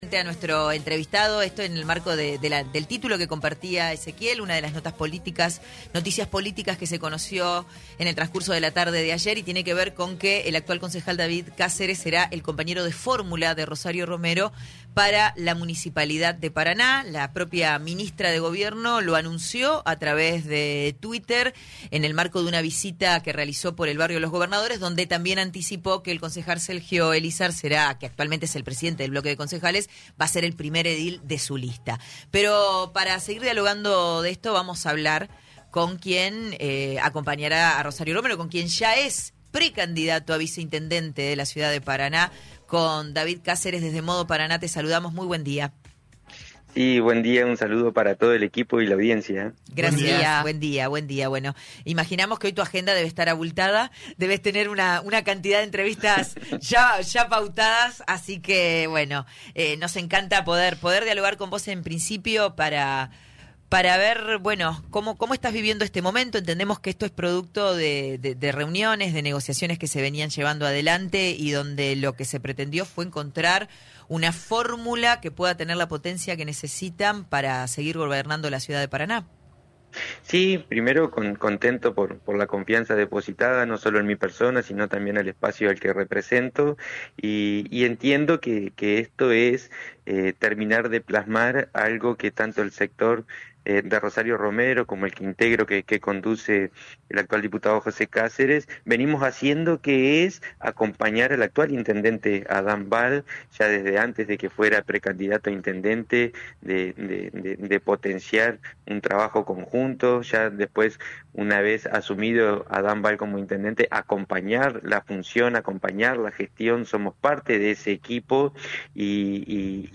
Somos parte de ese equipo y la idea de esta propuesta es consolidar su gestión y proponerle a la ciudadanía, a los vecinos y vecinas, una continuidad del trabajo que se viene desarrollando”, afirmó David Cáceres en diálogo con Modo Paraná en radio Costa Paraná .